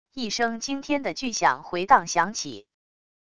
一声惊天的巨响回荡响起wav音频